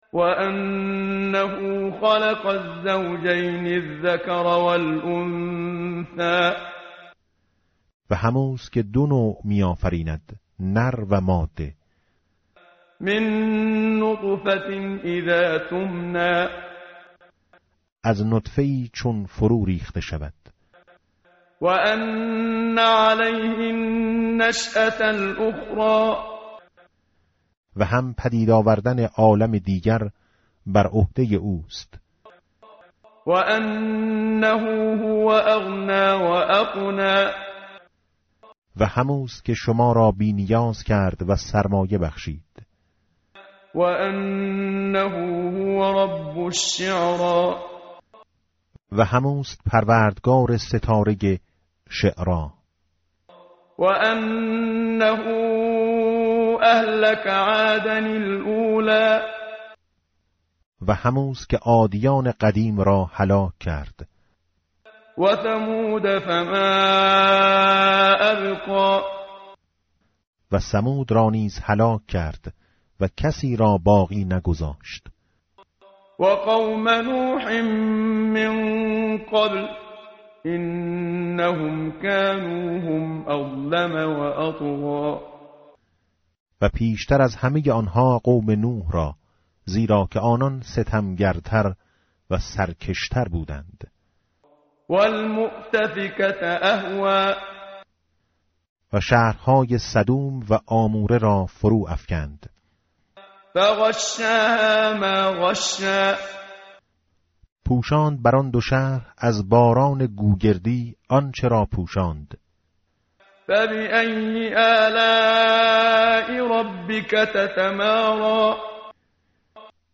متن قرآن همراه باتلاوت قرآن و ترجمه
tartil_menshavi va tarjome_Page_528.mp3